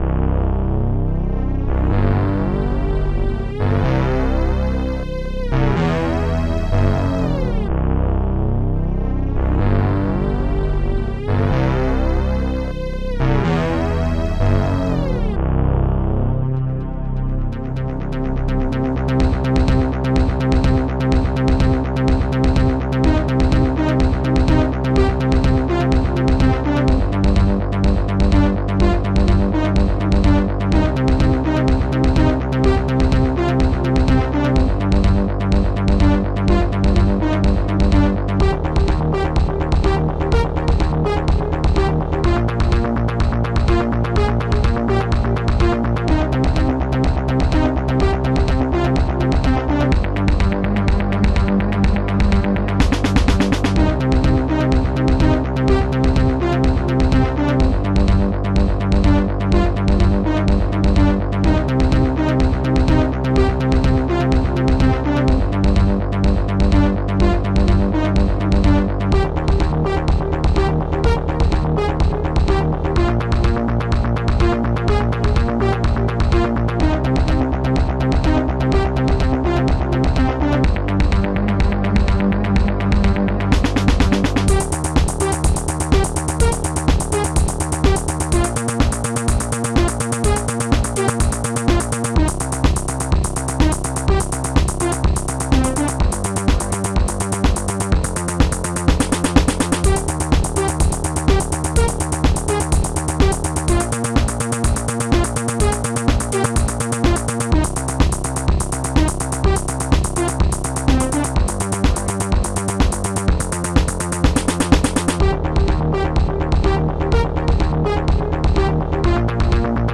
Protracker Module
analogstring strings2 squares bassdrum3 funbass hallbrass monobass hihat2 snare4